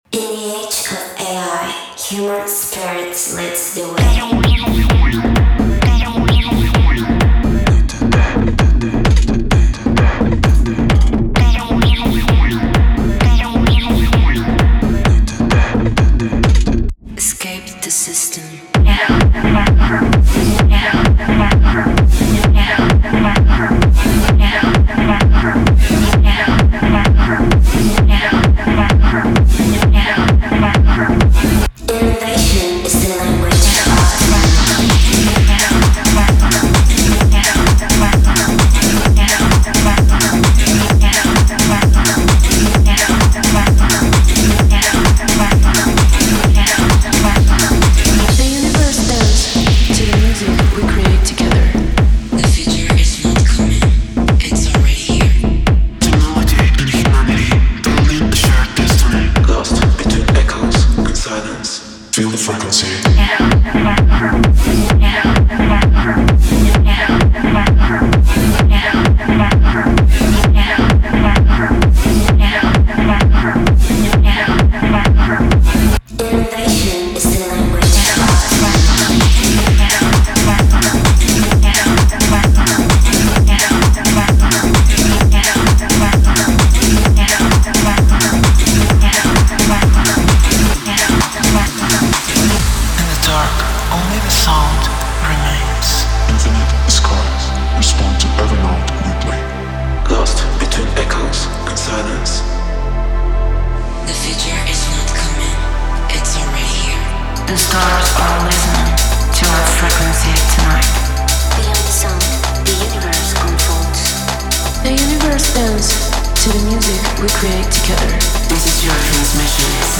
Cinematic / FX Vocals
Step into the sound of the future with this unique collection of spoken and glitch-processed vocals.
Inside you’ll find robotic phrases, AI-inspired speech, that explore themes like technology, the future, and digital evolution.
10 instrumental loops Drums, Bass, Synths
20 Vocals Glitch FX